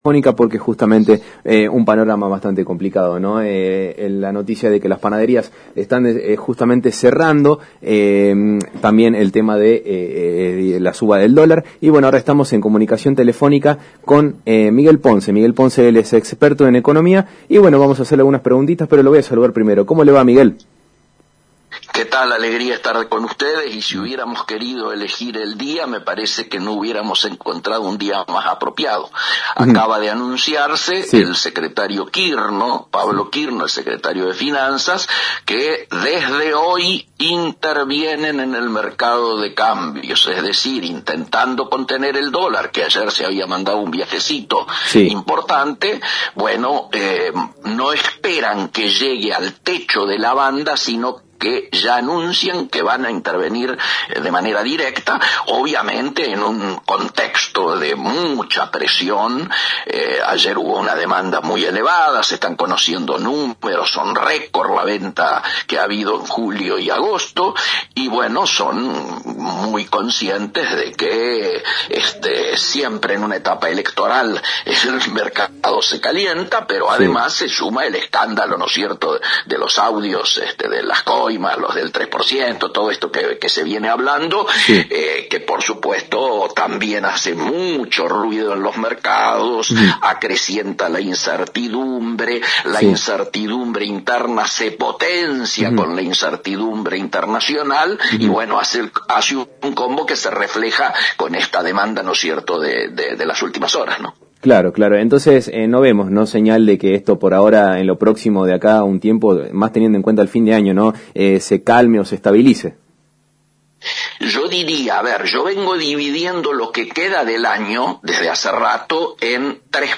ENTREVISTA AL ECONOMISTA Y EX FUNCIONARIO DE COMERCIO DE LA NACIÓN